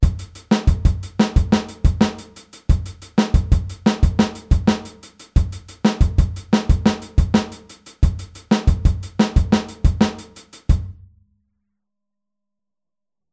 Aufteilung linke und rechte Hand auf HiHat und Snare